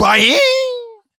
boing.wav